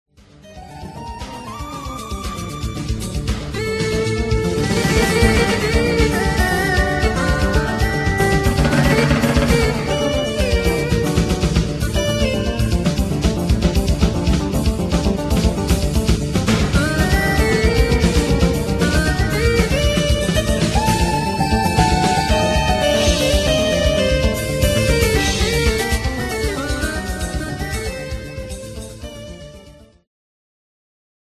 ジャンル Fusion
Progressive
アンビエント
宇宙系
フュージョンともジャズともプログレとも分類が出来ない奇妙な音の世界。しかしキワモノではなくサウンドトラック的な作品。